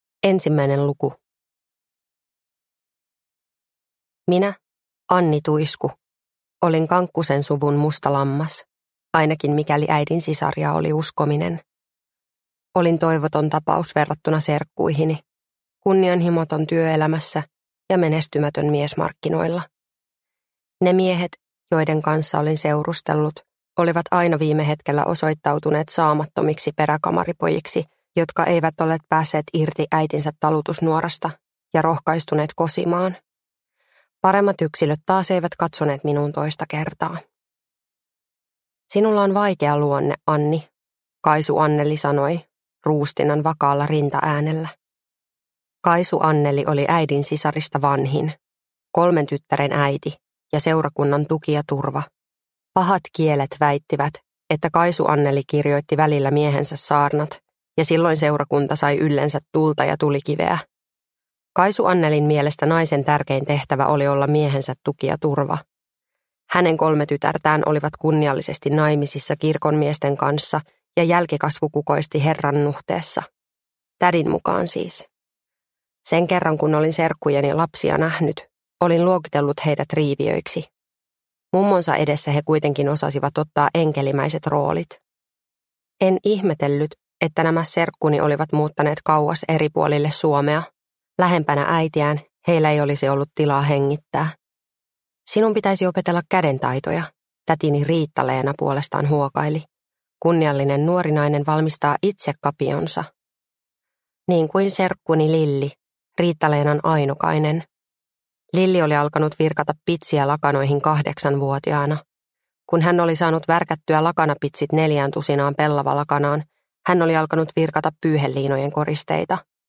Mies taskussa (ljudbok) av Tuija Lehtinen